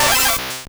Cri de Kabutops dans Pokémon Or et Argent.